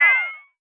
pew.wav